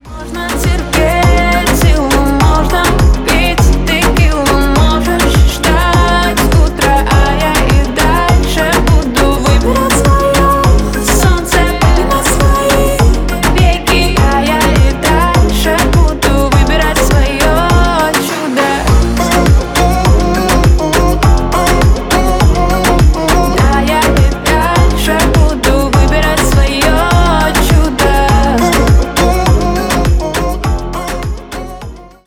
Поп Музыка
кавер